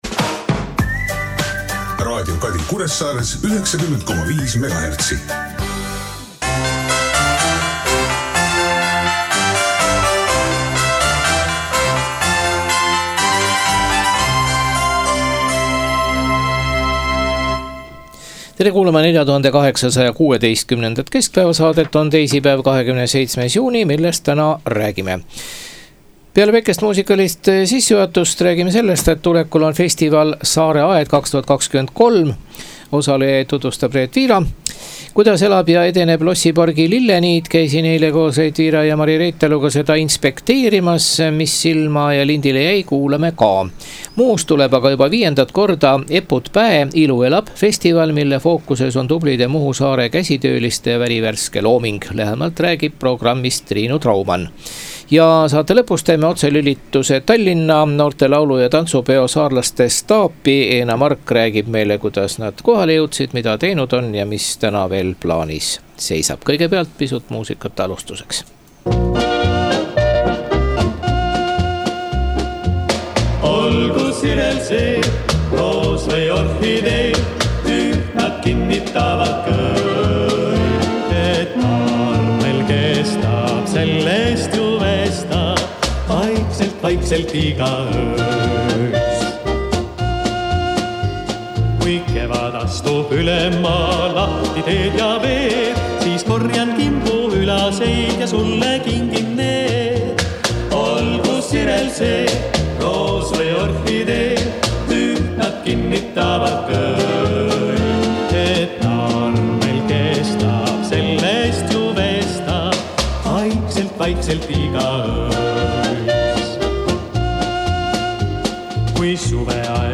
Otselülitus Tallinna, noorte laulu- ja tantsupeo saarlaste staapi.